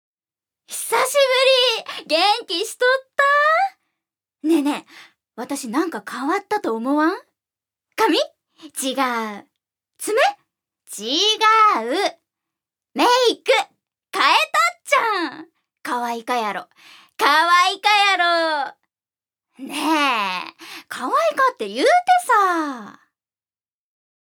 預かり：女性
音声サンプル
セリフ４